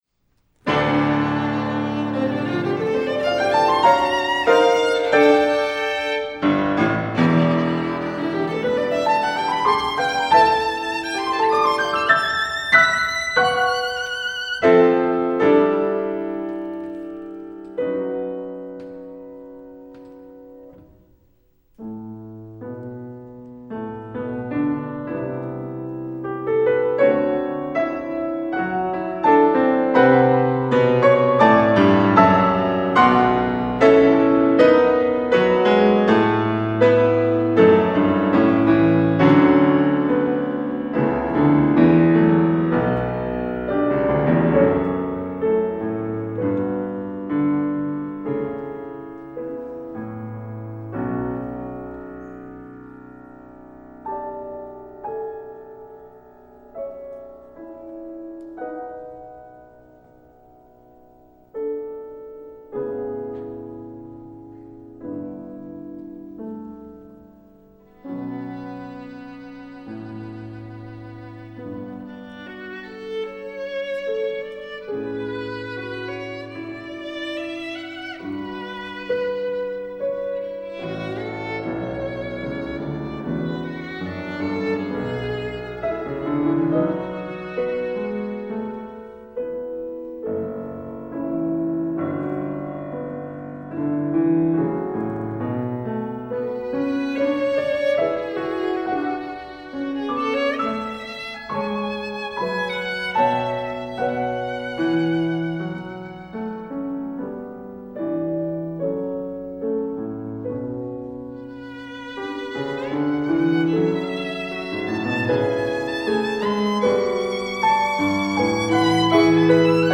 Voicing: Violin w/P